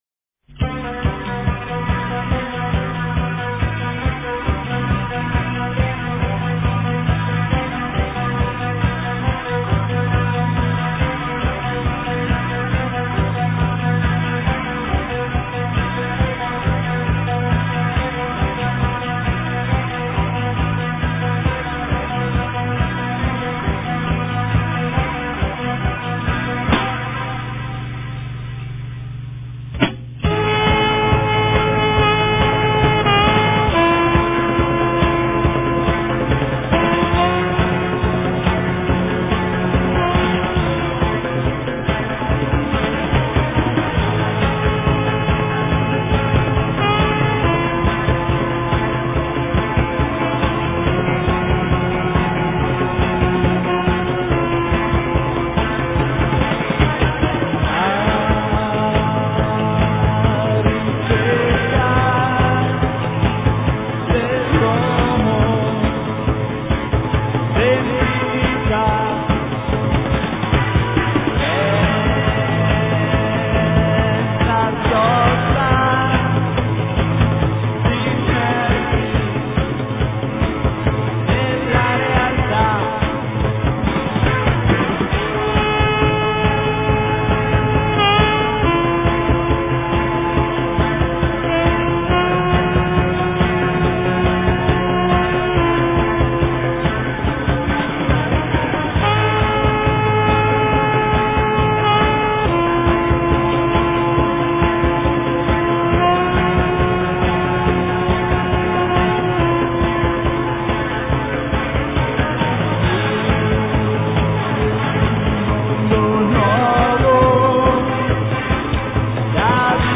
voce, sax, oboe, tastiere, chitarra
basso, voce
batteria, piano